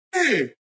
SPolicemanWarn.ogg